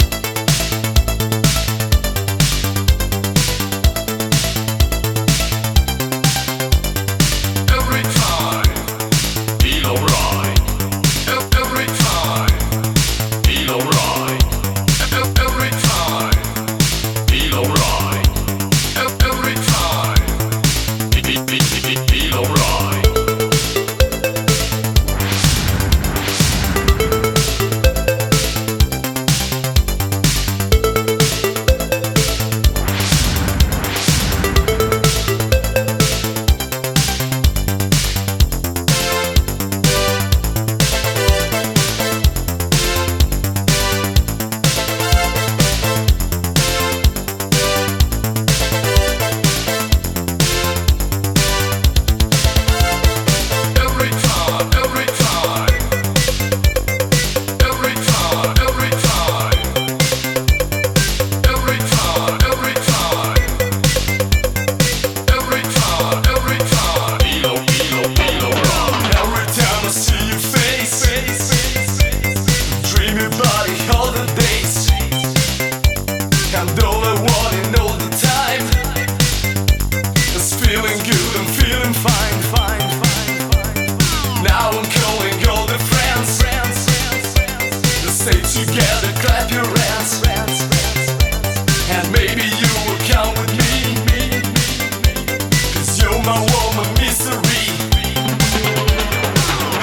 2010年にリリースされていたとは俄には信じ難い、まんま1984年か1985年の音。